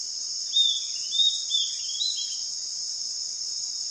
Mosquitero (Corythopis delalandi)
Nombre en inglés: Southern Antpipit
Localización detallada: Camino desde el pueblo al río (puerto Viejo)
Condición: Silvestre
Certeza: Vocalización Grabada
Mosquitero.mp3